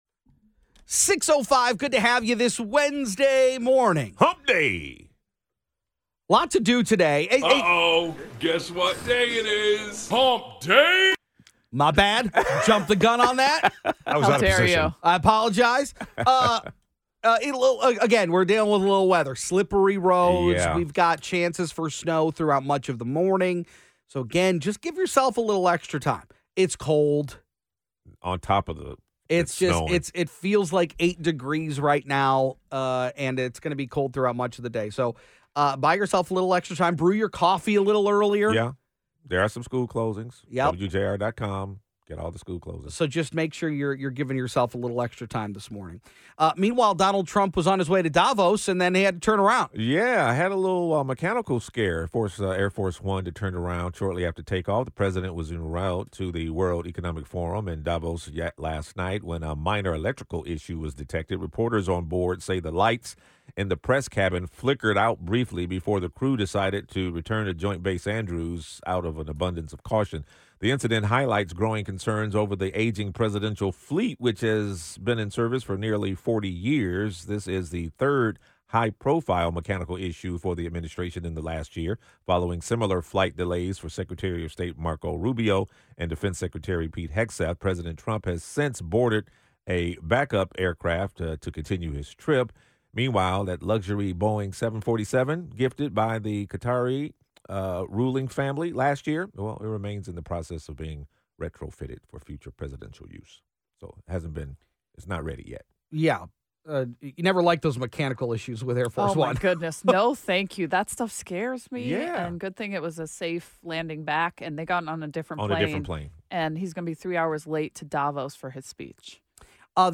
and global news live from High-Atop the Golden Tower of the Fisher Building. Listen in as the newsmakers who know, alongside our team of reporters and commentators, bring you the topics that are shaping the future in Southeast Michigan. Get the latest on government and politics, business news, area happenings, sports, entertainment news, and more!